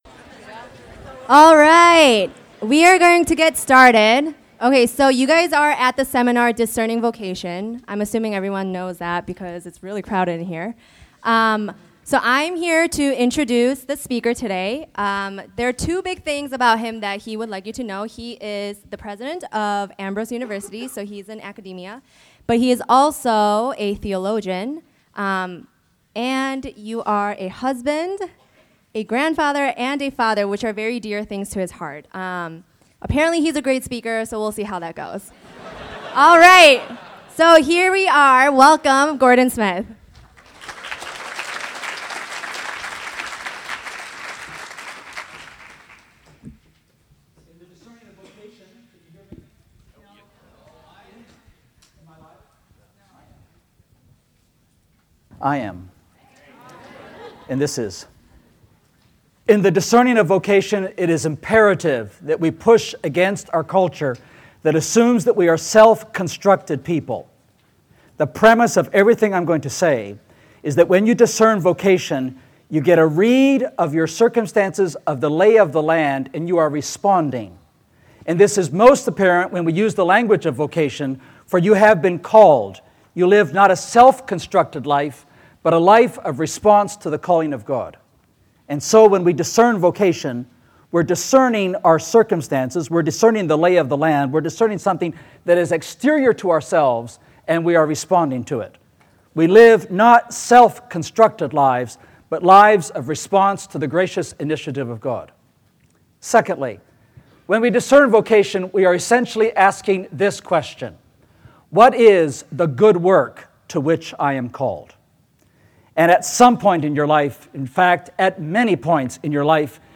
This seminar considers the six defining questions that we need to ask ourselves on our journey to discover the answer to this particular question.